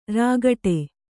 ♪ rāgaṭe